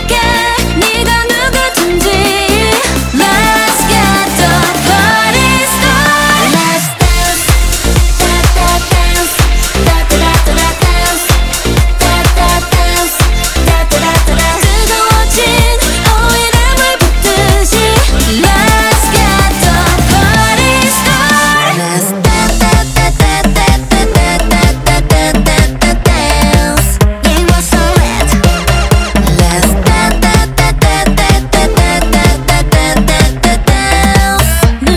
Жанр: K-pop / Поп / Русские